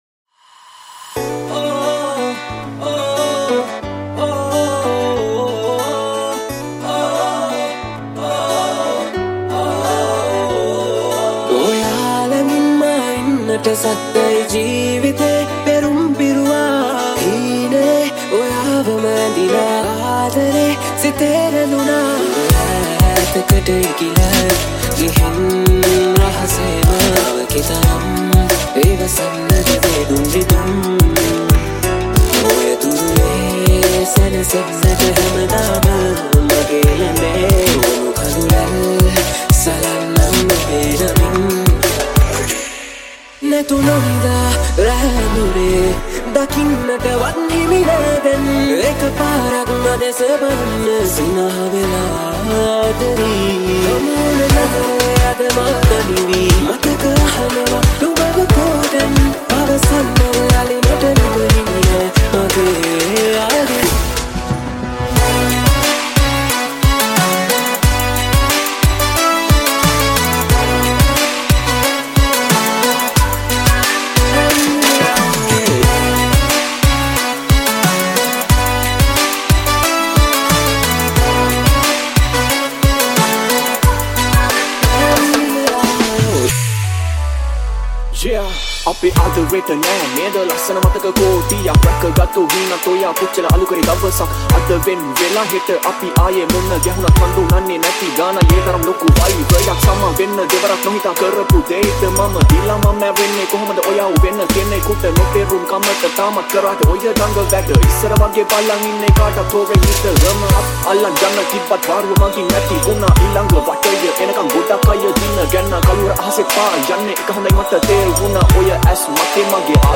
Remix New Song